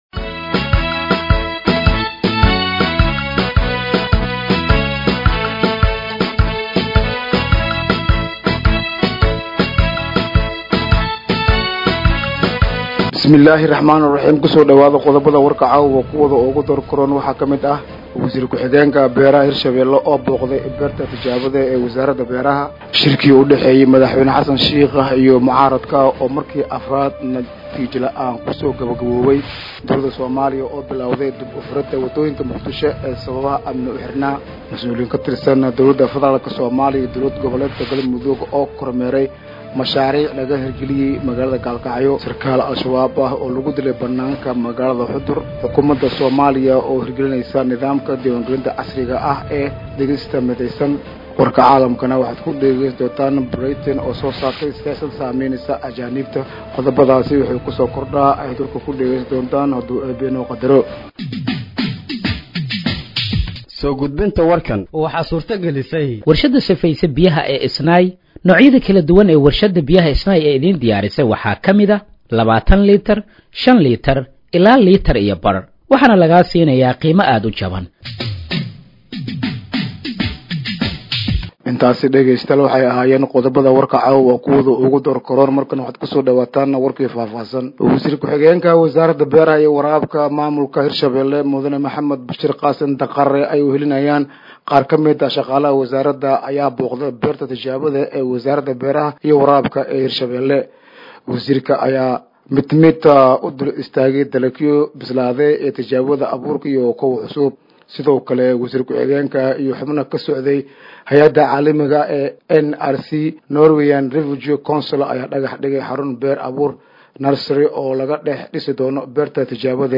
Dhageeyso Warka Habeenimo ee Radiojowhar 10/08/2025
Halkaan Hoose ka Dhageeyso Warka Habeenimo ee Radiojowhar